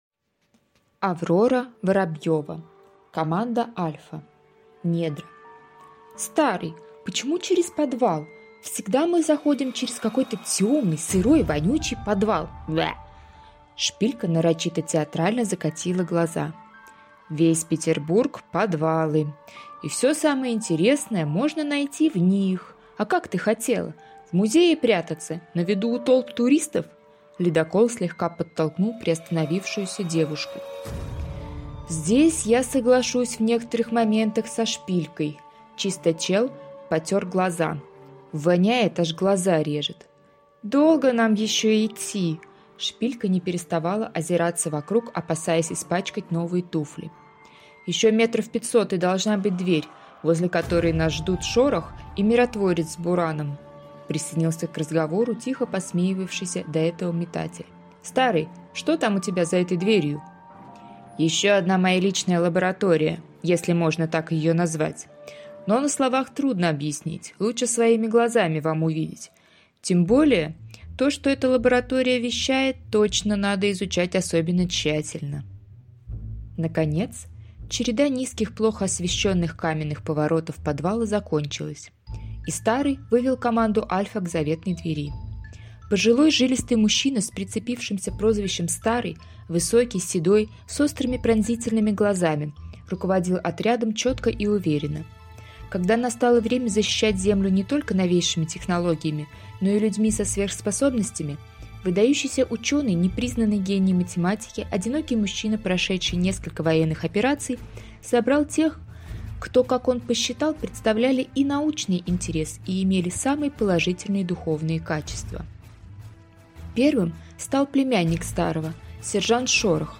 Аудиокнига Команда «Альфа». Недра | Библиотека аудиокниг